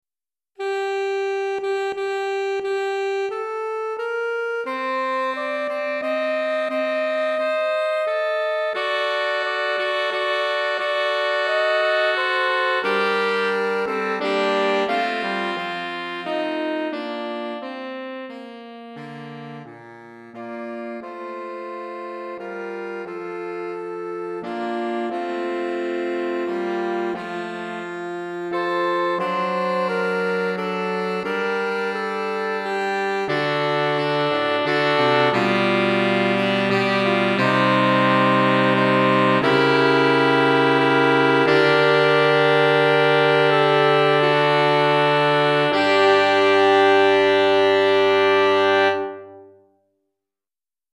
4 Saxophones (Soprano, Alto, Ténor, Baryton)